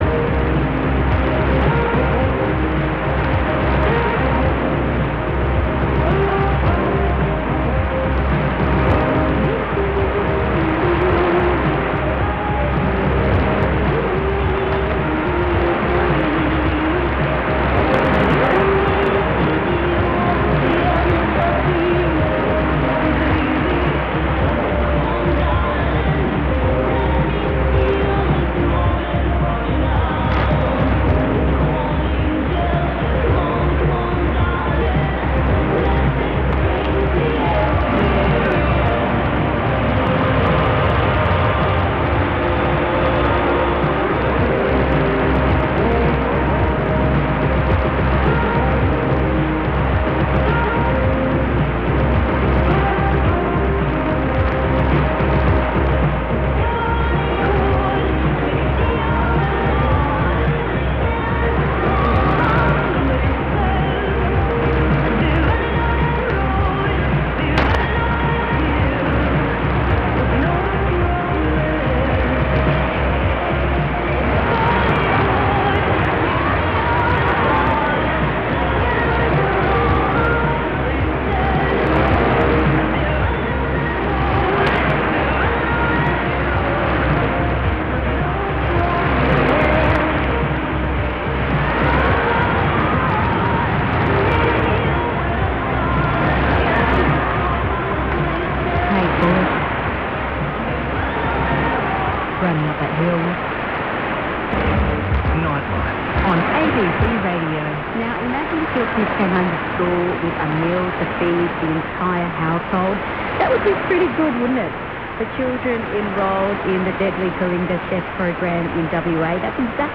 Yes, there is a bit of hash here too, not as bad as some other places I’ve stayed in.
Very interesting catch tonight on 774 on the TEF while 3LO is on reduced power, an English Lesson on the 500kW powerhouse JOUB Akita, Japan (NHK Radio 2) at around 8350km.
Unfortunately looks like 3LO is back on air now, judging by how it goes back into it at around 1:40.